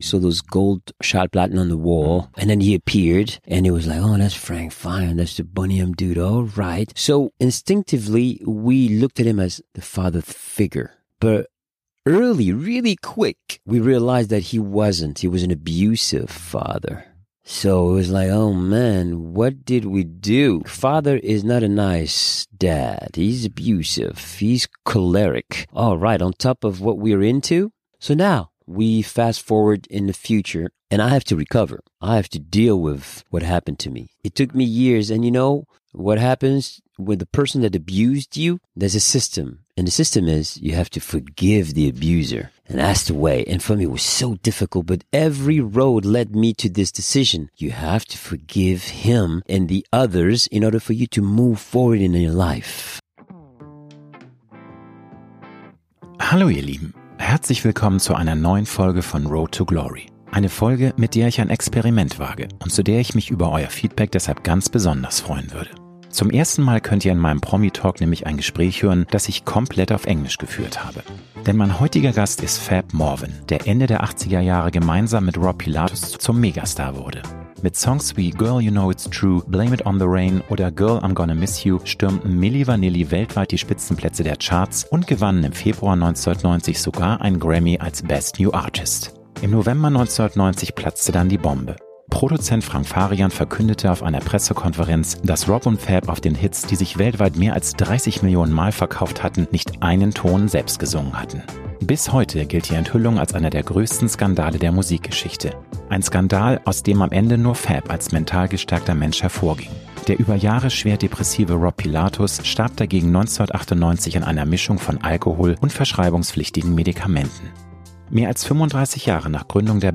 Beschreibung vor 2 Jahren Mit meiner neuen Folge von ROAD TO GLORY wage ich ein Experiment, zu dem ich mich über euer Feedback ganz besonders freuen würde: Zum ersten Mal könnt ihr in meinem Promi-Talk nämlich ein Gespräch hören, das ich auf englisch geführt habe.